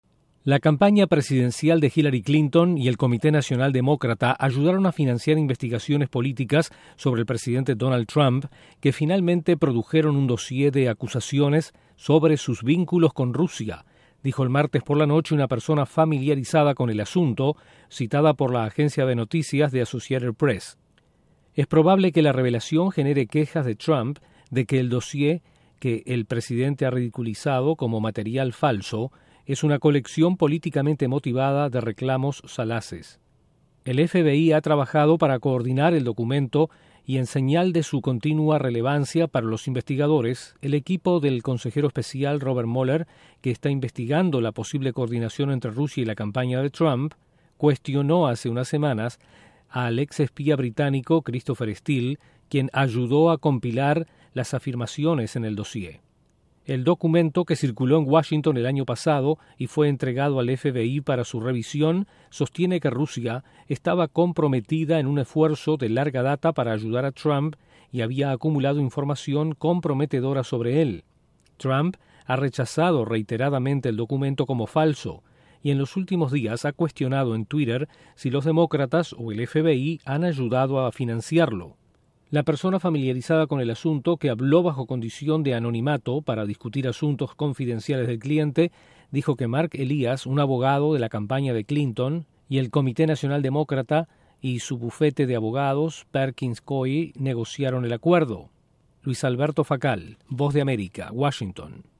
La campaña de Clinton y el Comité Nacional Demócrata habrían financiado dossier contra Donald Trump. Desde la Voz de América en Washington informa